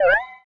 low_health_beep_03.wav